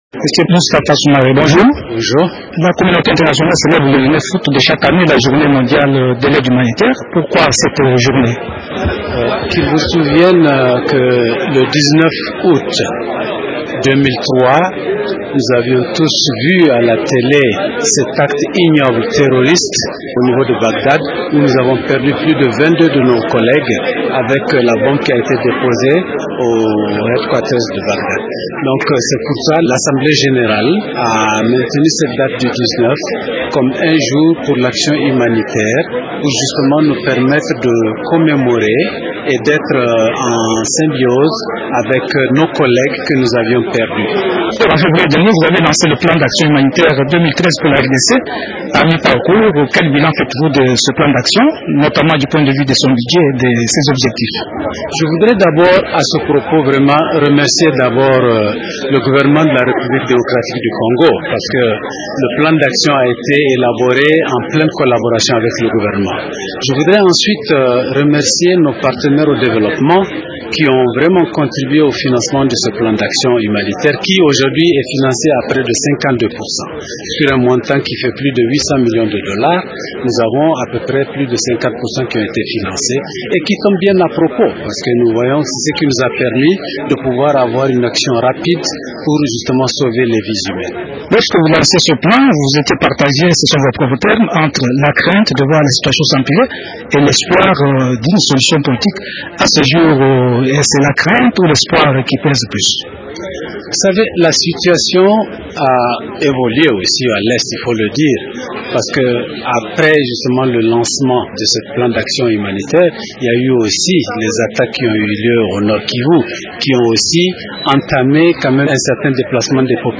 M. Soumaré répond aux questions